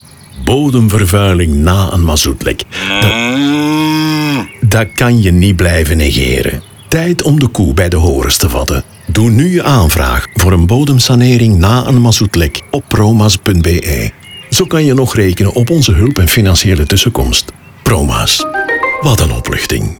240507_Promaz_NL20s_Radio_01.mp3